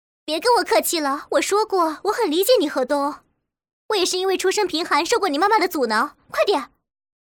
影视语音
巴恭：约18岁，不谙世事的大学生形象。音色年轻而充满朝气，语调轻松愉快，充满了对未来的憧憬和希望，给人一种积极向上的感觉。
唯帕薇：约20岁，心机深重的女性形象。 音色年轻华丽，略带鼻音，契合混血身份。她的声音透着神秘和诱惑力，通过细微变化传达情感。